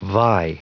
Prononciation du mot vie en anglais (fichier audio)
Prononciation du mot : vie